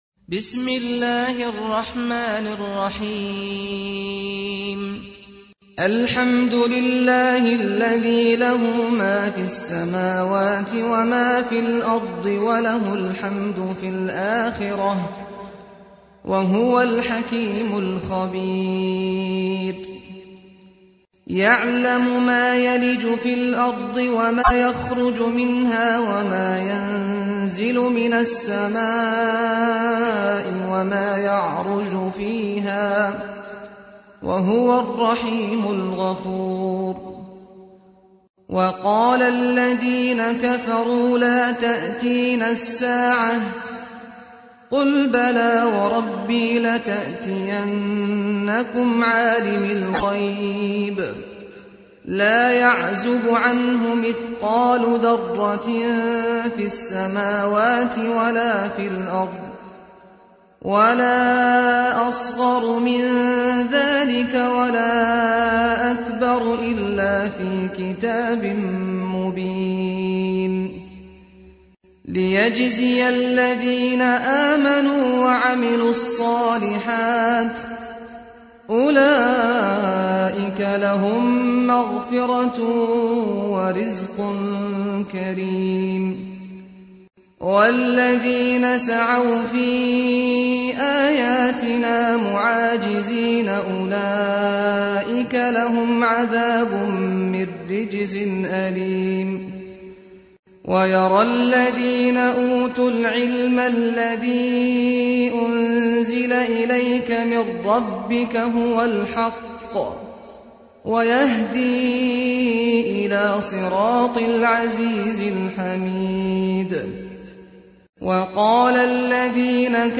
تحميل : 34. سورة سبأ / القارئ سعد الغامدي / القرآن الكريم / موقع يا حسين